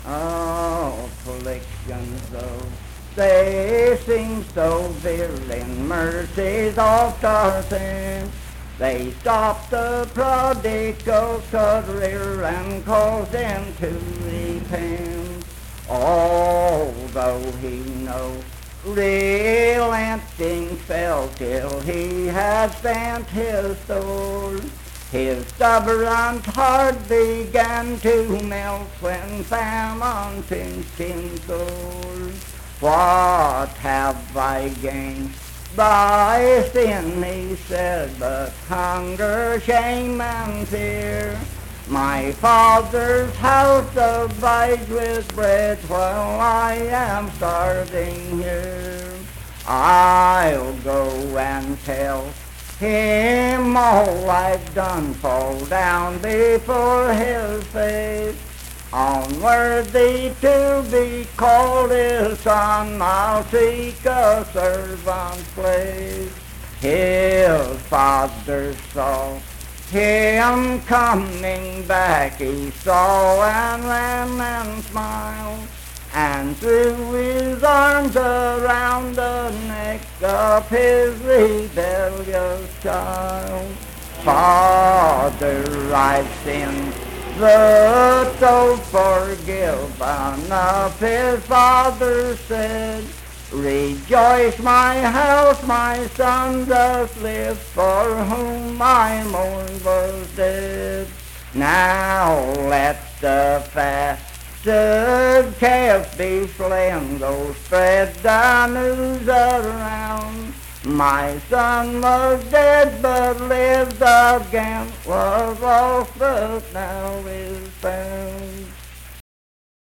Unaccompanied vocal music performance
Hymns and Spiritual Music
Voice (sung)
Spencer (W. Va.), Roane County (W. Va.)